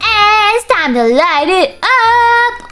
Worms speechbanks
Orders.wav